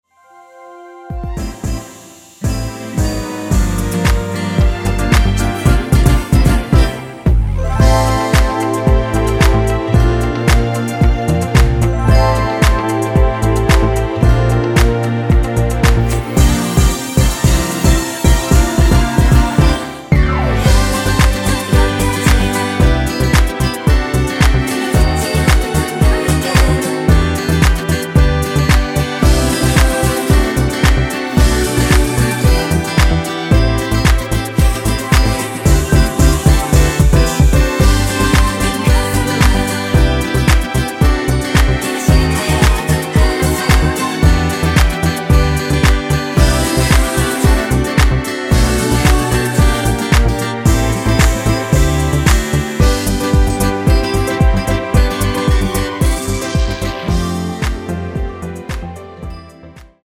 (-1) 내린 코러스 포함된 MR 입니다.(미리듣기 참조)
앞부분30초, 뒷부분30초씩 편집해서 올려 드리고 있습니다.
중간에 음이 끈어지고 다시 나오는 이유는
곡명 옆 (-1)은 반음 내림, (+1)은 반음 올림 입니다.